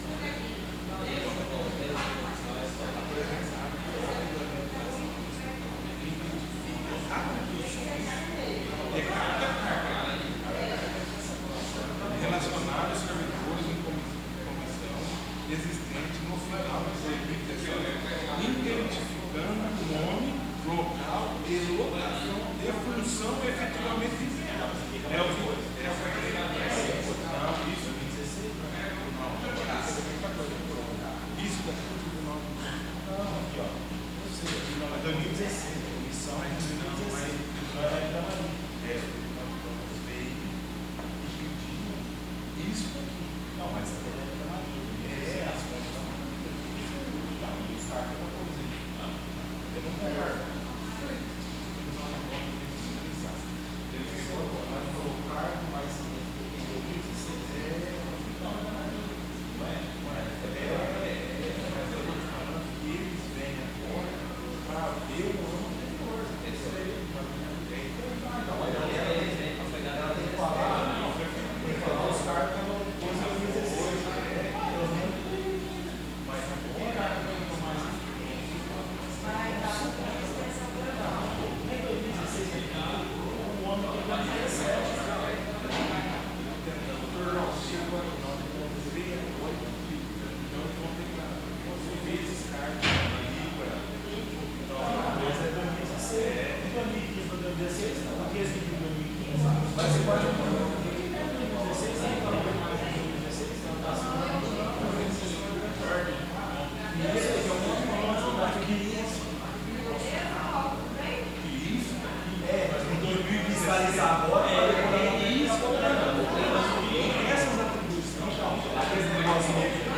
Áudio da 6° Sessão Ordinária – 18/04/2017